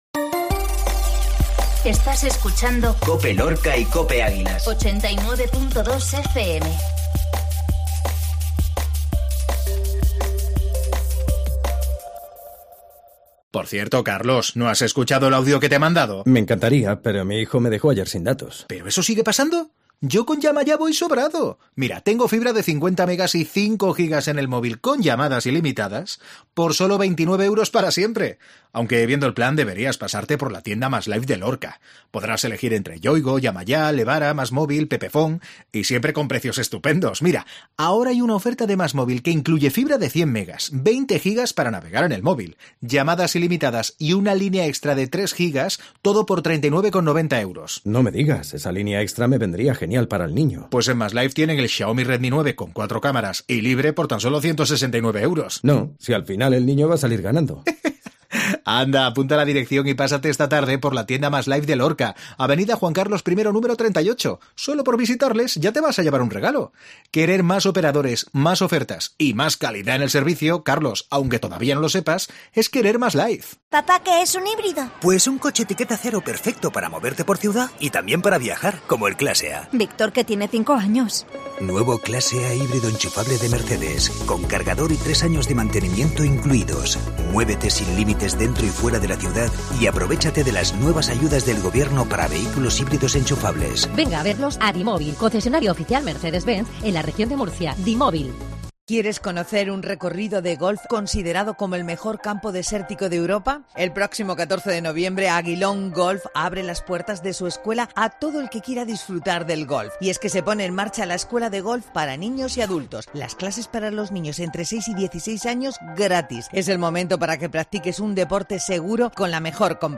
INFORMATIVO MEDIODIA COPE JUEVES